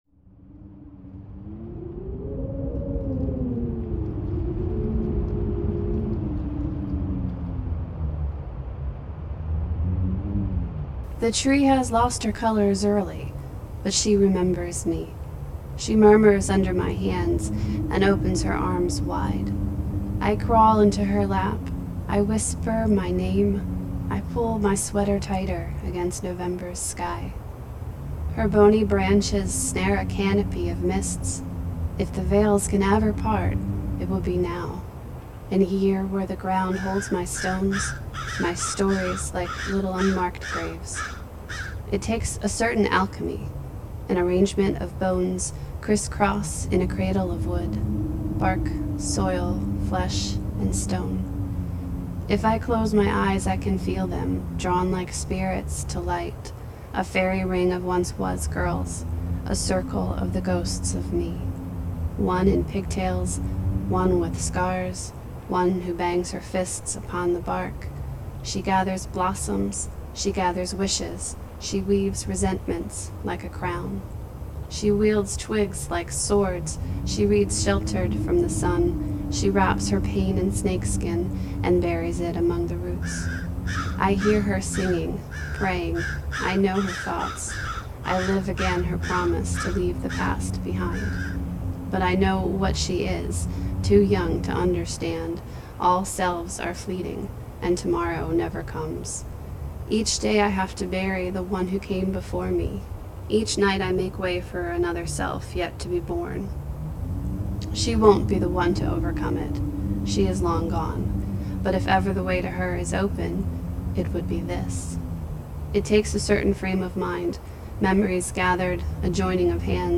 2013 Halloween Poetry Reading